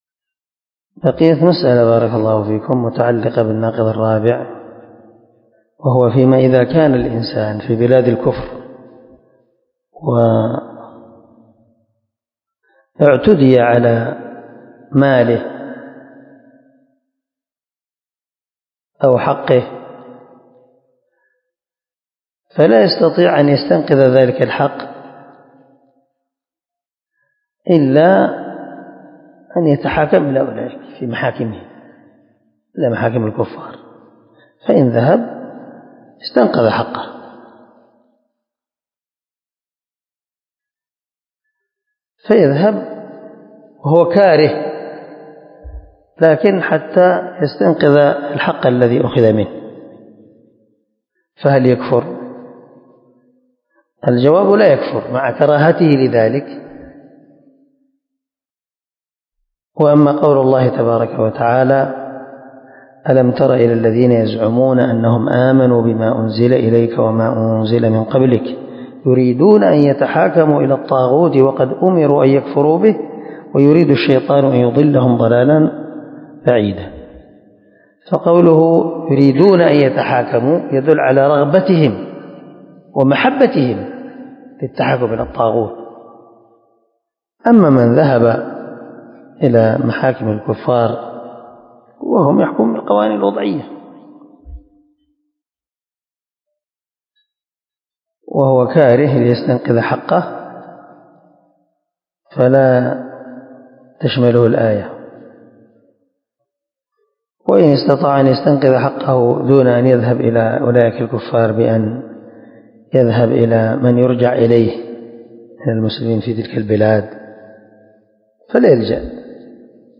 الدرس-15-الناقض-الخامس.mp3